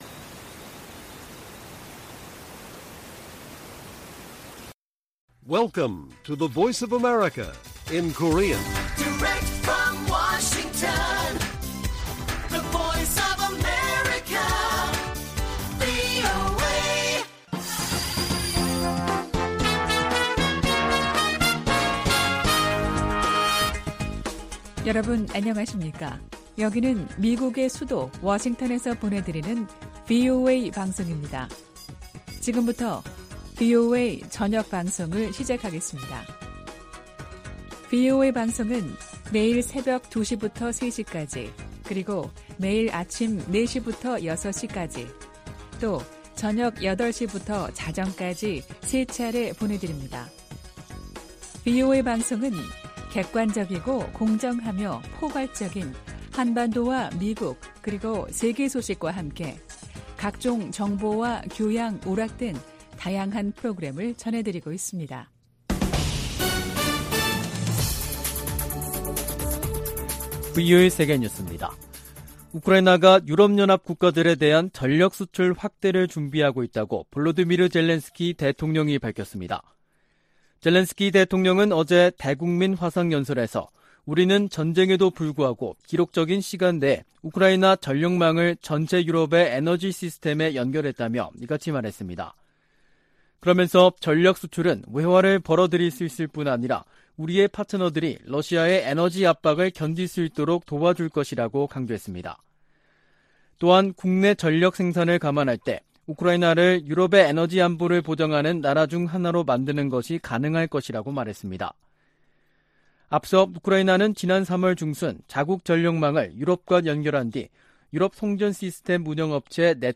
VOA 한국어 간판 뉴스 프로그램 '뉴스 투데이', 2022년 7월 28일 1부 방송입니다. 미국은 북한 정부 연계 해킹조직 관련 정보에 포상금을 두배로 올려 최대 1천만 달러를 지급하기로 했습니다. 북한의 핵 공격 가능성이 예전에는 이론적인 수준이었지만 이제는 현실이 됐다고 척 헤이글 전 미 국방장관이 평가했습니다. 김정은 북한 국무위원장이 미국과 한국을 강력 비난하고, 미국과의 군사적 충돌에 철저히 준비할 것을 다짐했다고 관영 매체들이 보도했습니다.